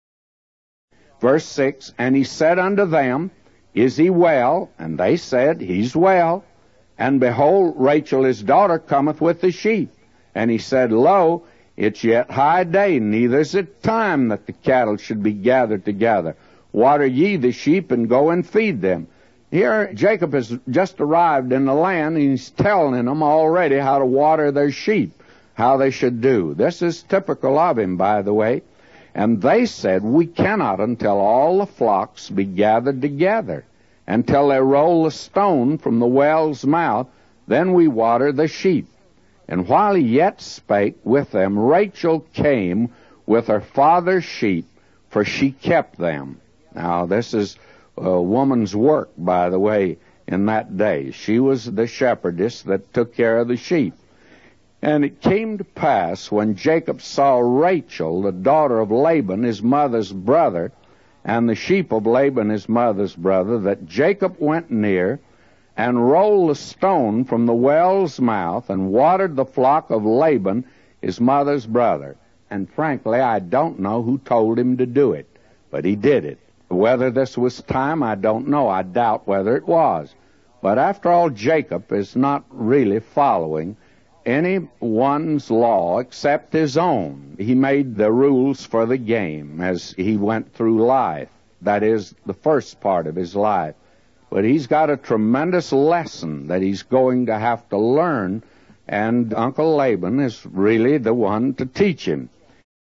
In this sermon, the speaker focuses on the story of Jacob arriving in a new land and encountering his cousin Rachel.